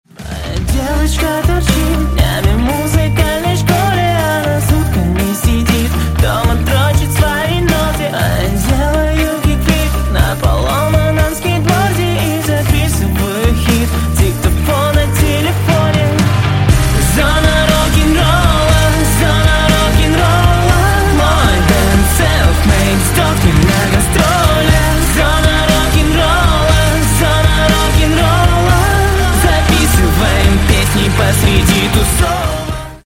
Альтернатива
Рок Металл